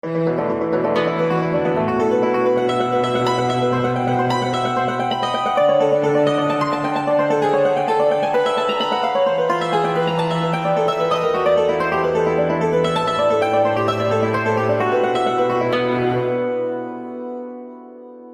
chipmusic